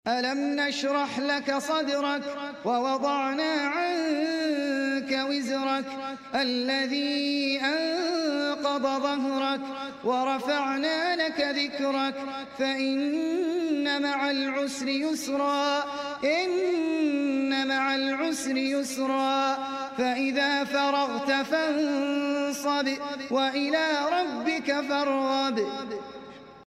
Recited Mp3 Sound Effect Surah Ash-Sharh 1-8 Ayah. Recited by Ahmad Al Ajmi.